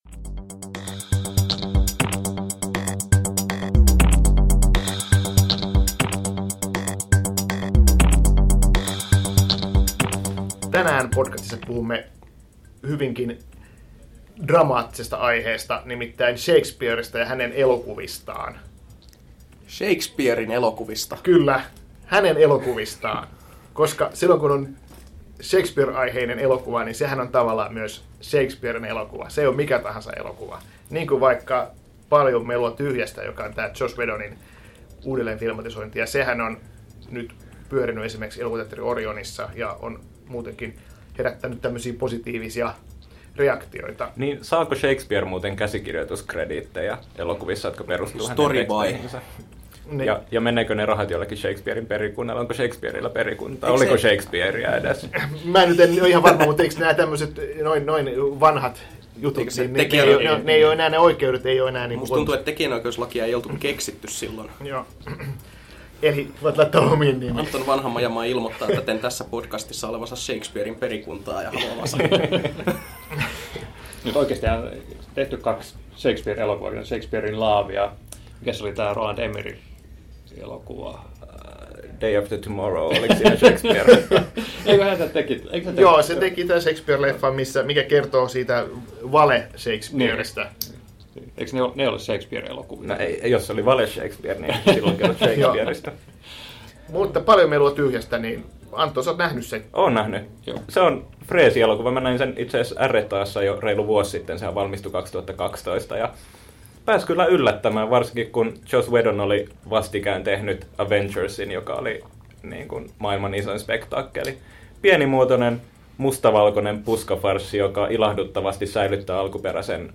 Shakespeare-keskustelu.mp3